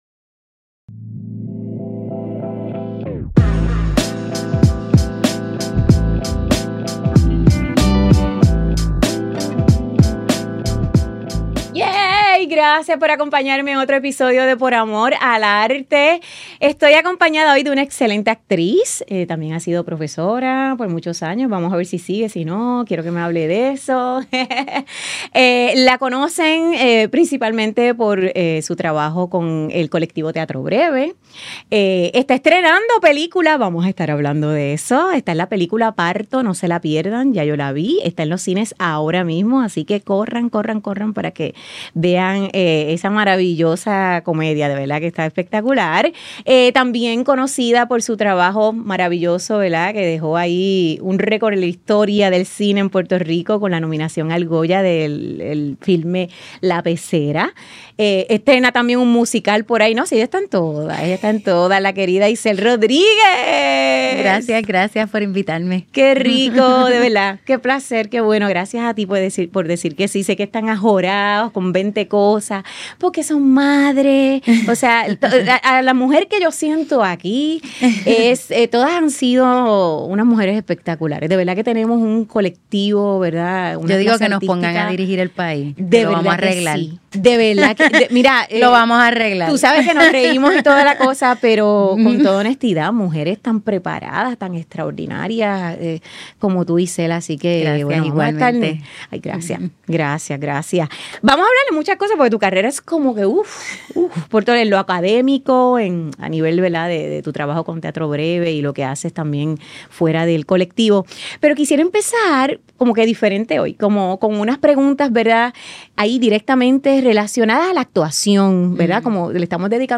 Grabado en GW-Cinco Studio para GW5 Network